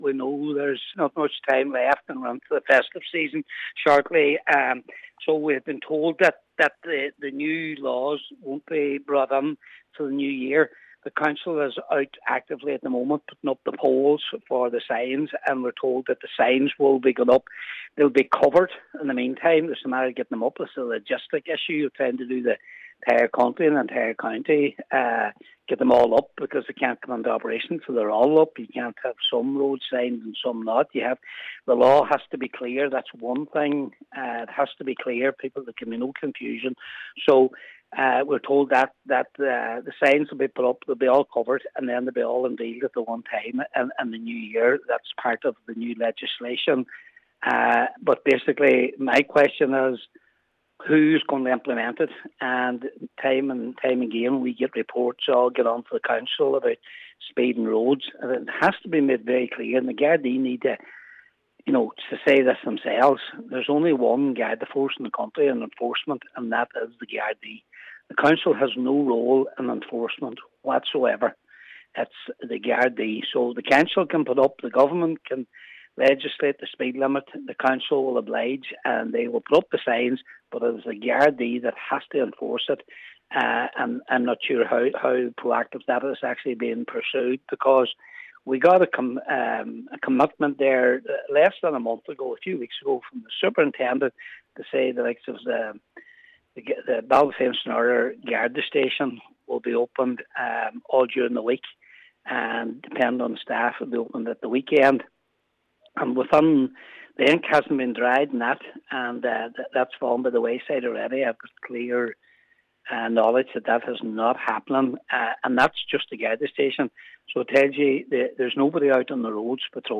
Cllr Patrick McGown says, however, that without proper enforcement, the sign changes will be in vain: